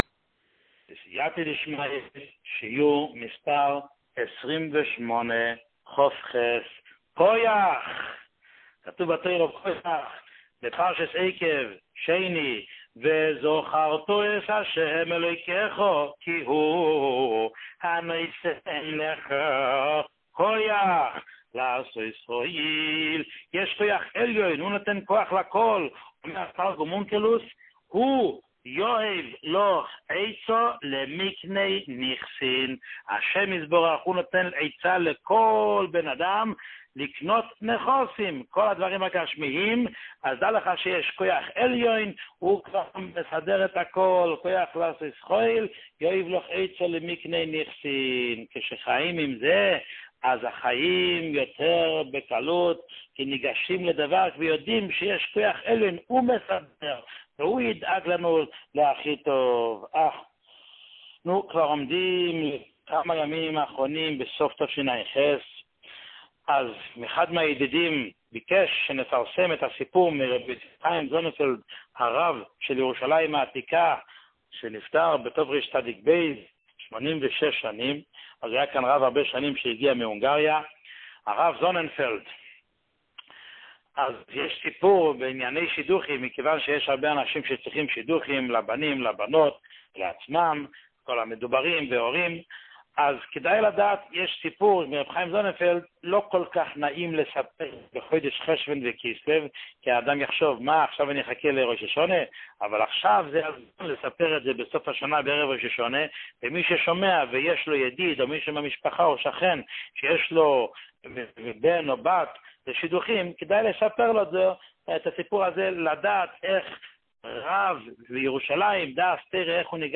שיעור 28